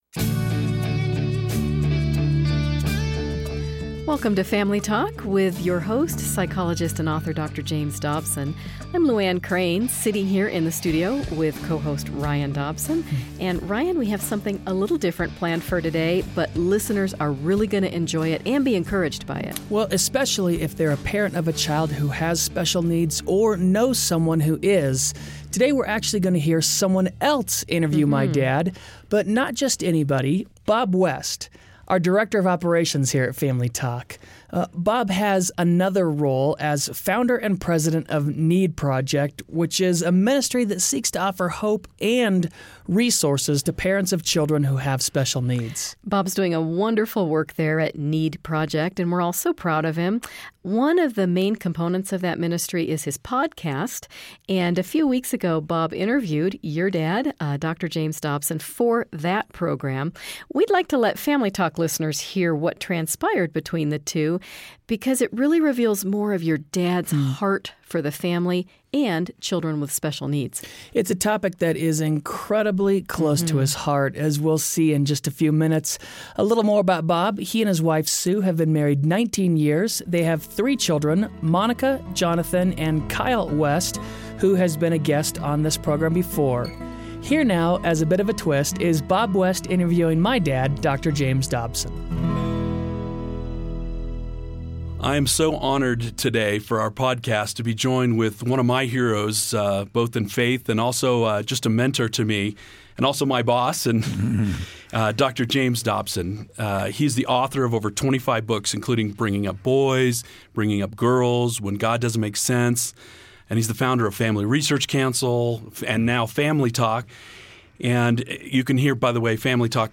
Listen today as Dr. James Dobson delivers a sobering address on the state of the American family and the culture war that's being waged against our nation's youth. He then extends a strong challenge for God's people to reach out to hurting families, especially those raising disabled children.